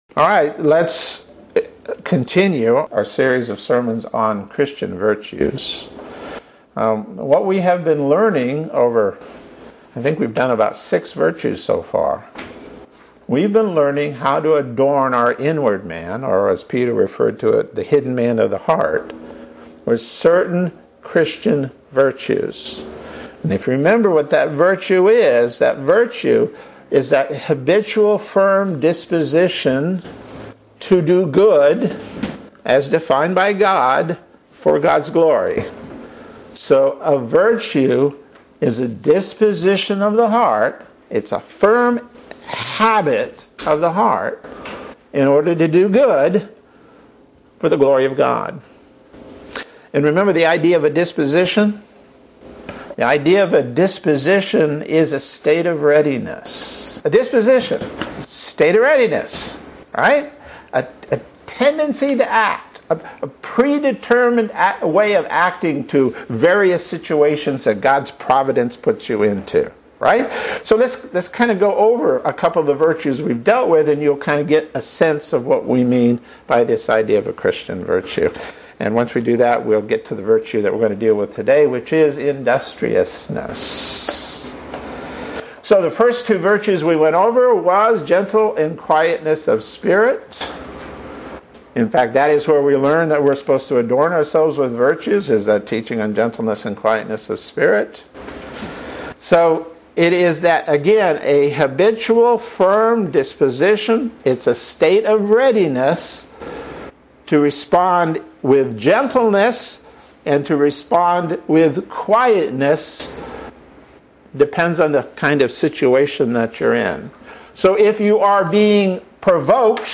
Sermons | Reformed Presbyterian Church of Ocala